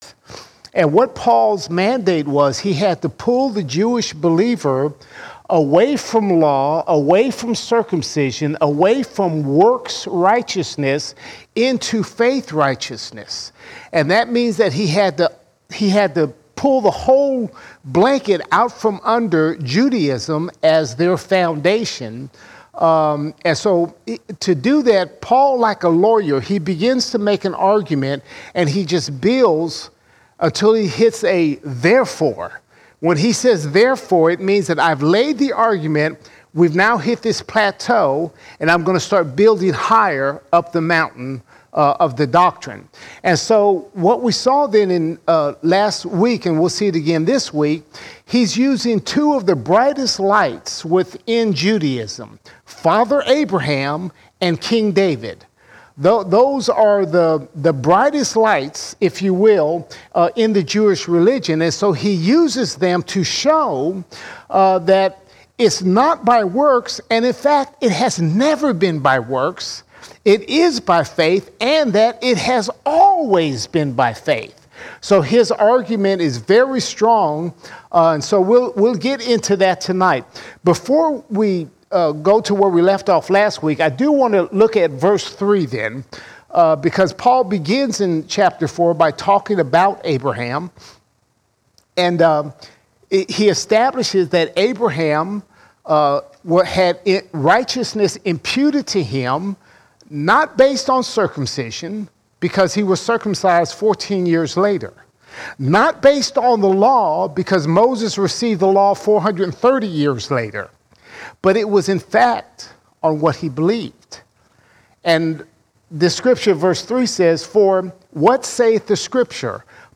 3 August 2023 Series: Romans All Sermons Romans 4:9 to 4:25 Romans 4:9 to 4:25 We see the importance of faith in our salvation.